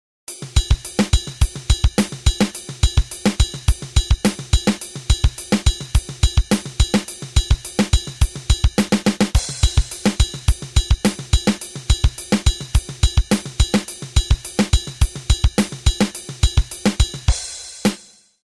This is a confounding groove. There is no kick on the downbeat, and the ride accent is on the offbeat, creating a pattern that feels good to play and makes your guitar player look dumb when he comes in an eighth-note late.
Either way, this is a 4-limbed pattern.
Emphasizing the offbeat helps throw the perception of time off by an eighth, if you’re into that sort of thing.